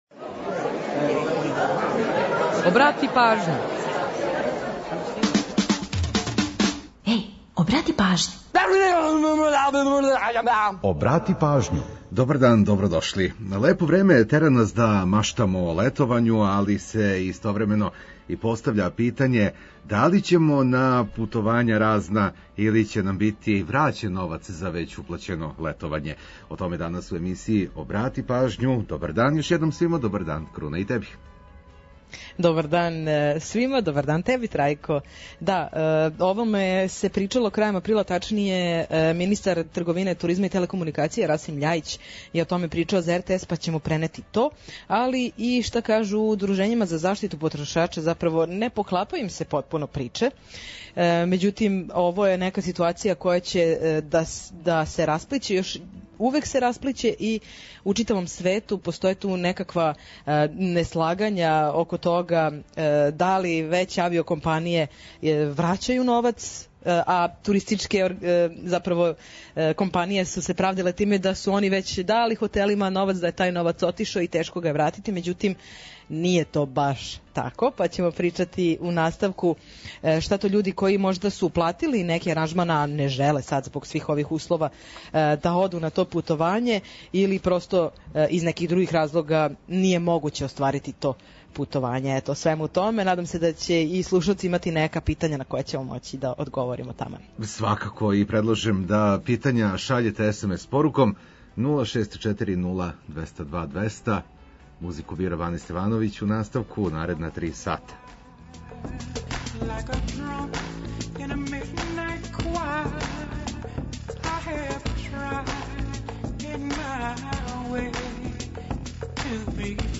Ту је и пола сата резервисаних само за нумере из Србије и региона.